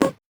Index of /musicradar/8-bit-bonanza-samples/VocoBit Hits
CS_VocoBitC_Hit-11.wav